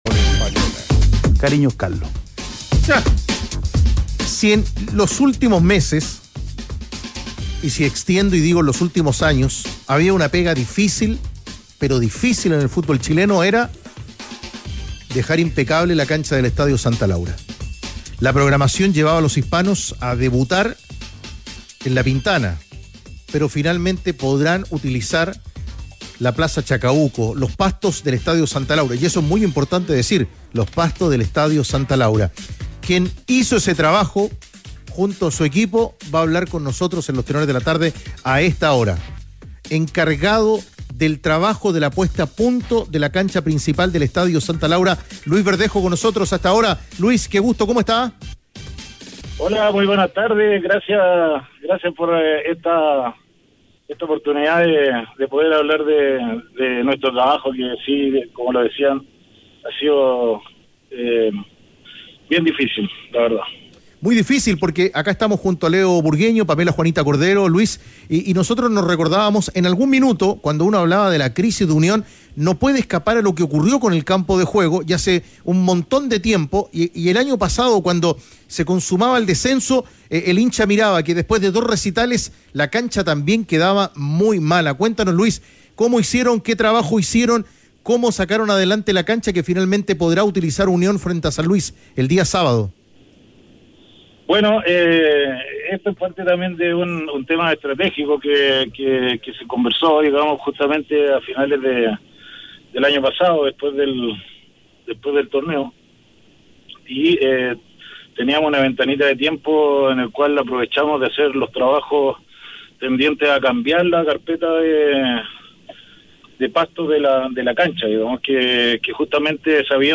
En conversación con Los Tenores de la Tarde